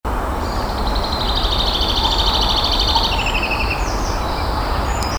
Птицы -> Вьюрковые ->
зяблик, Fringilla coelebs